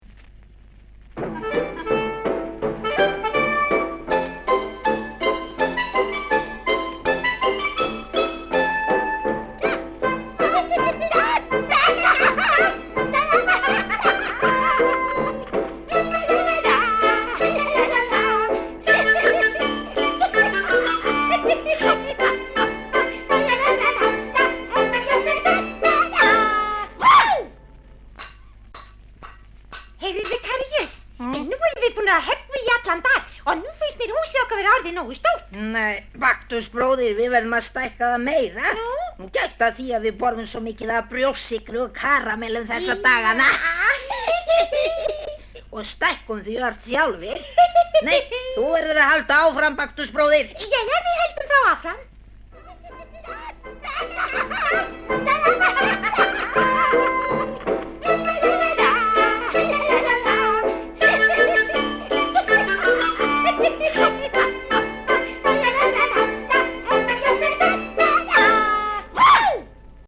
barnaleikrit með söngvum.